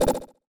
Message Bulletin Echo 8.wav